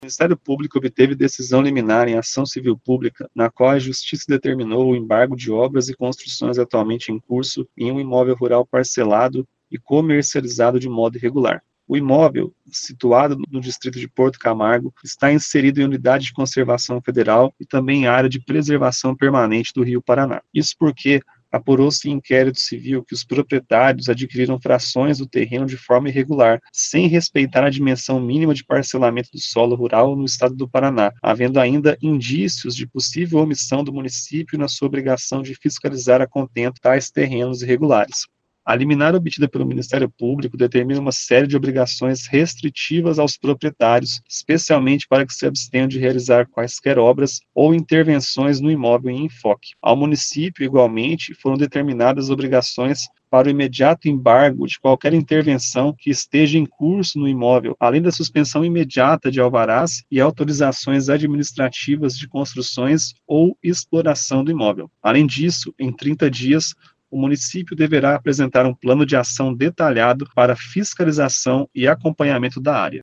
Ouça o que diz o promotor de Justiça, Rafael Vittorazze Azola: